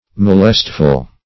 Molestful \Mo*lest"ful\, a. Troublesome; vexatious.
molestful.mp3